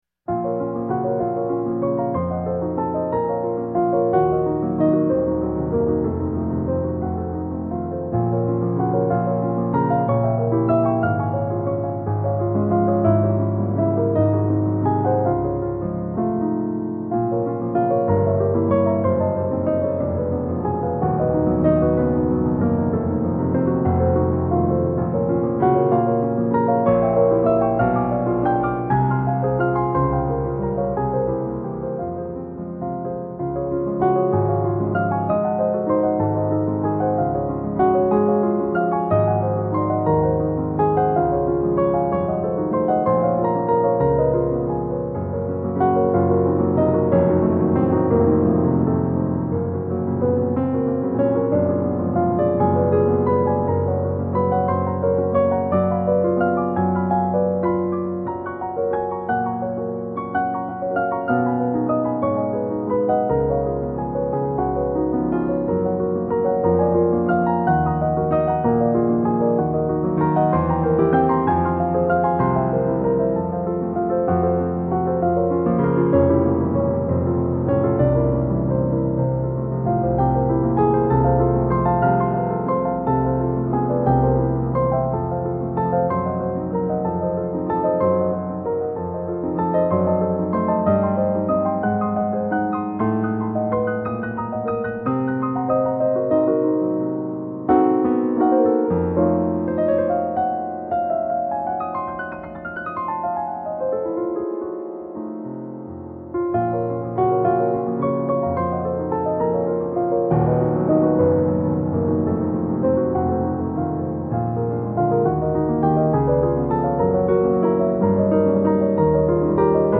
I used to play one of his 12 Etudes. I think it was the last one, in B-Flat Minor.
Damn I could play this sucker.